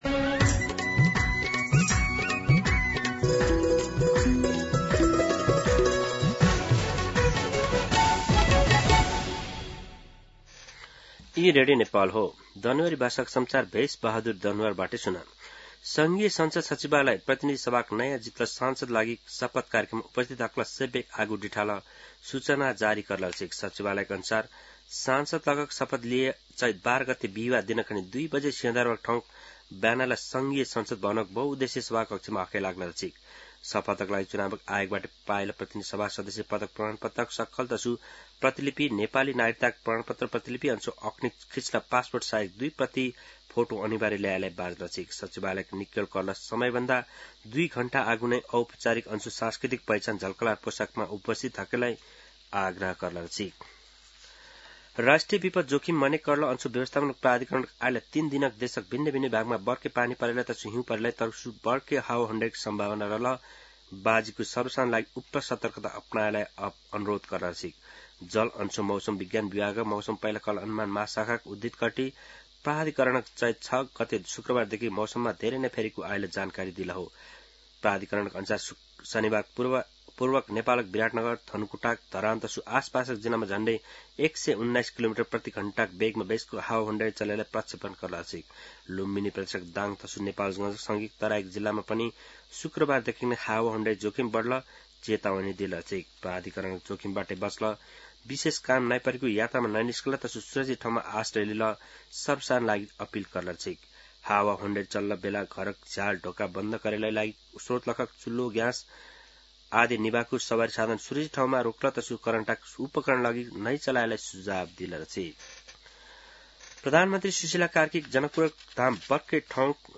दनुवार भाषामा समाचार : ६ चैत , २०८२
Danuwar-News-12-6.mp3